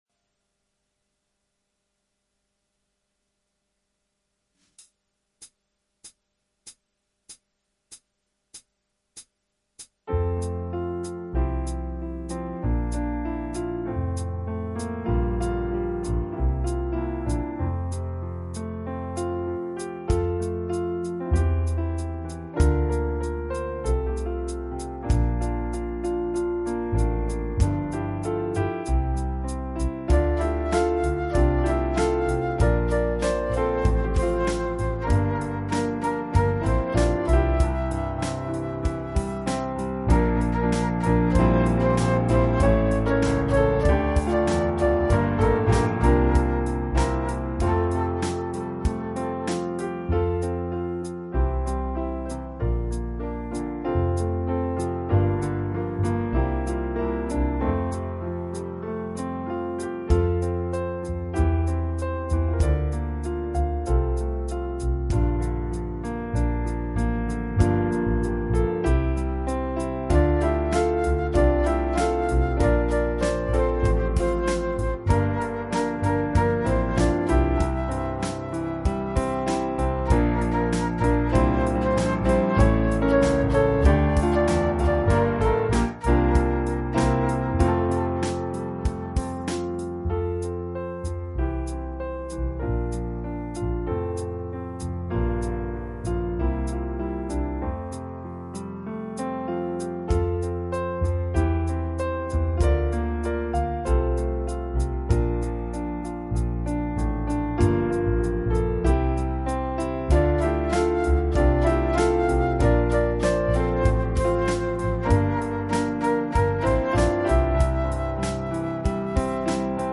Skolas himnas pavadījums:
BMMP-Himna-Instrumental-2.mp3